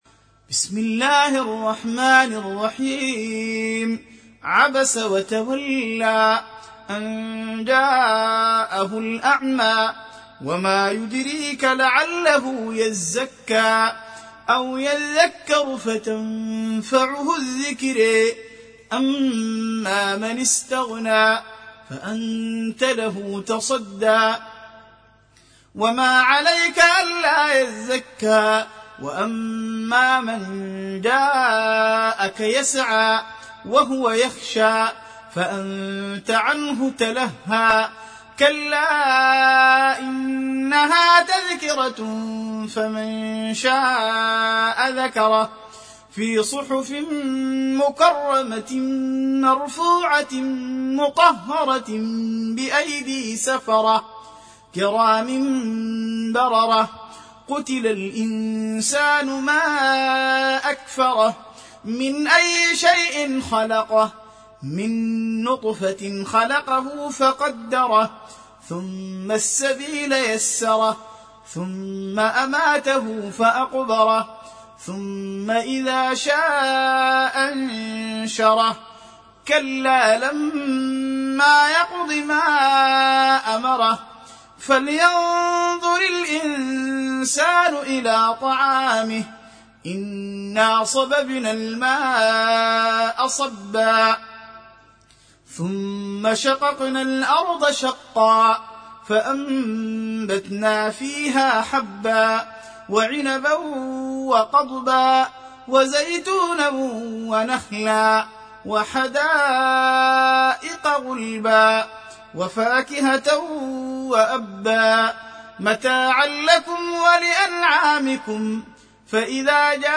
80. Surah 'Abasa سورة عبس Audio Quran Tarteel Recitation
Surah Sequence تتابع السورة Download Surah حمّل السورة Reciting Murattalah Audio for 80. Surah 'Abasa سورة عبس N.B *Surah Includes Al-Basmalah Reciters Sequents تتابع التلاوات Reciters Repeats تكرار التلاوات